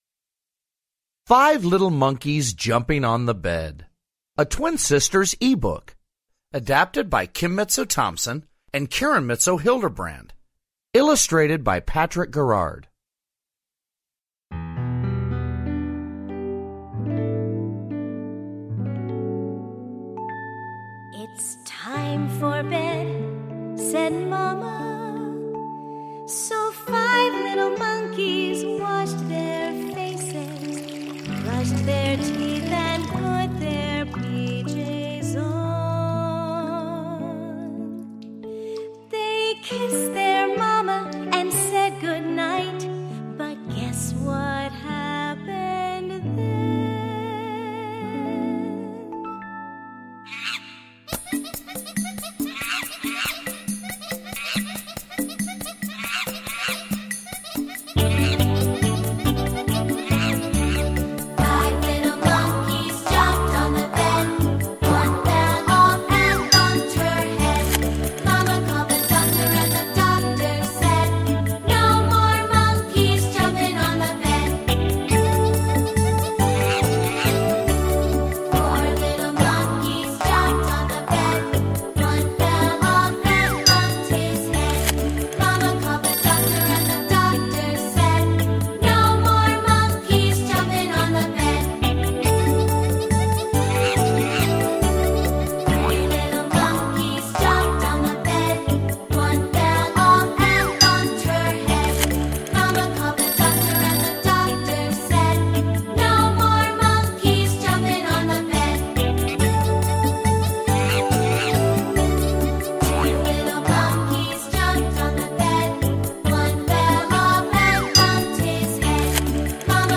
Reading Five Little Monkeys Jumping On The Bed